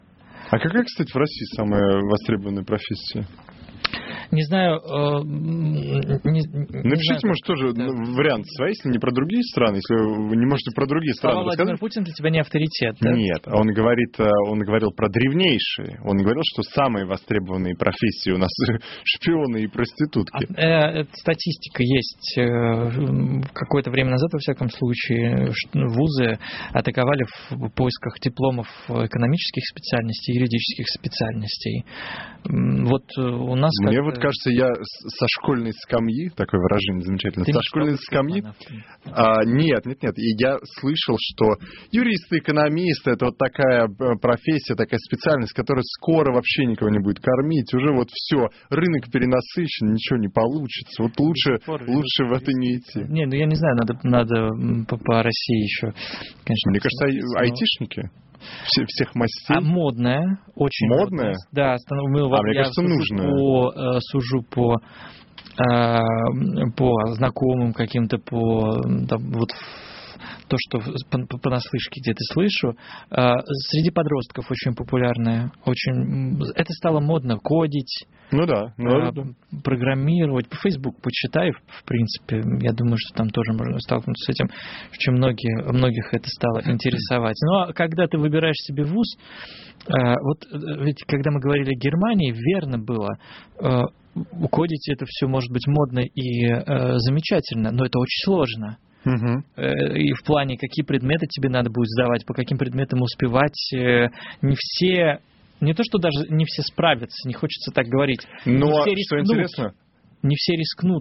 Для подтверждения своих слов приведу отрывки из программы "Популярные и востребованные профессии: опыт разных стран" радиостанции Эхо Москвы.